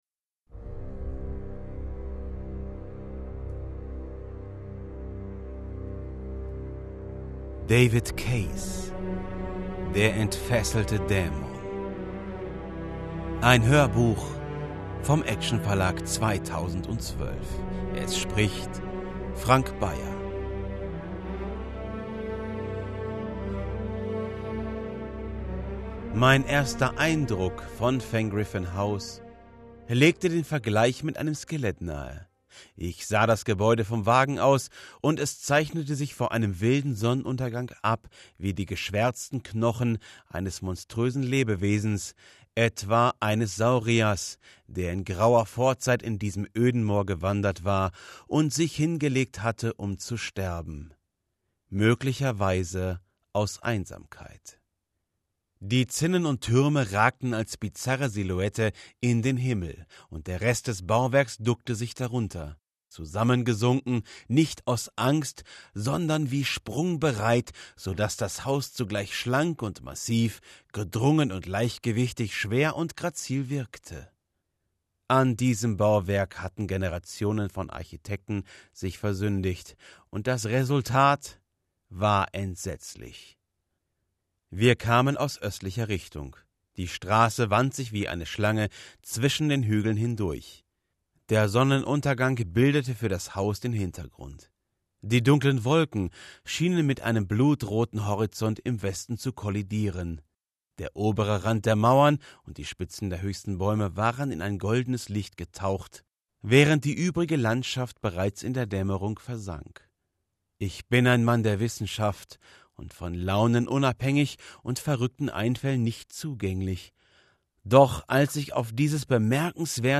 Ich spreche mit einen warmen, seichten Ton, kann aber auch in den Bass Bereich abtauchen.
Dämon Hörspiel